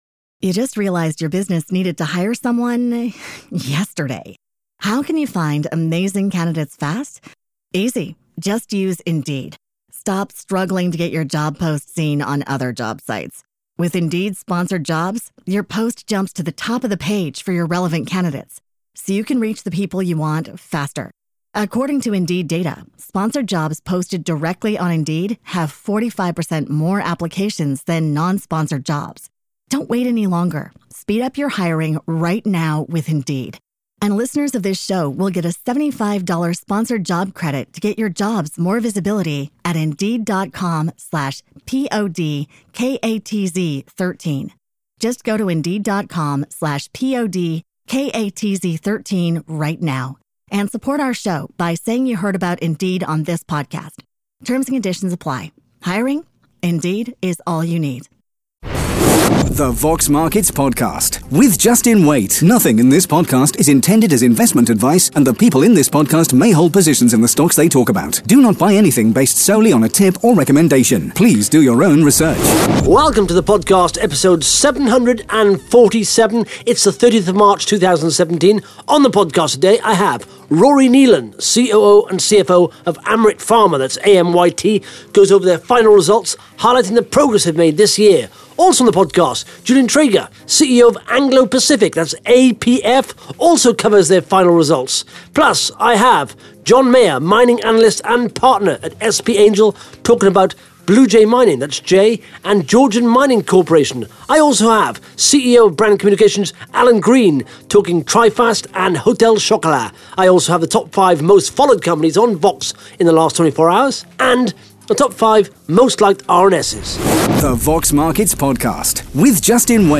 (Interview starts at 58 seconds)